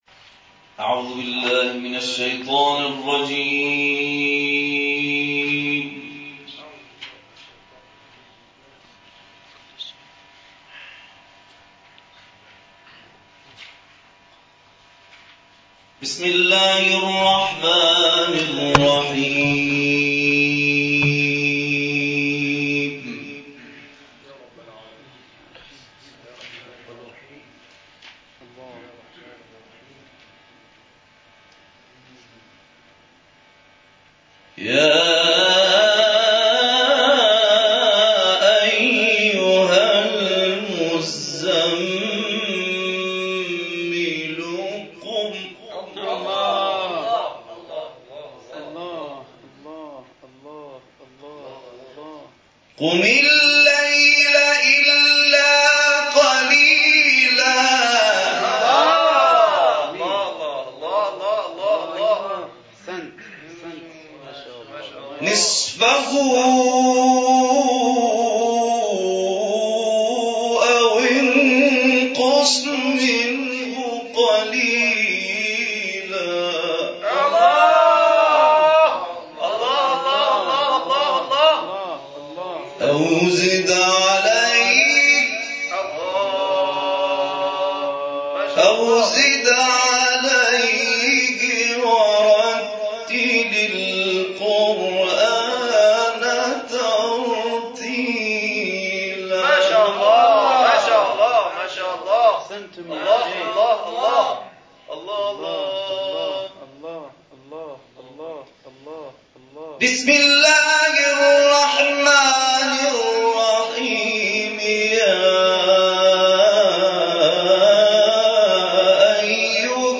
این تلاوت در اول بهمن ماه در مسجد القرآن کاشان اجرا شده است و مدت زمان آن 21 دقیقه است.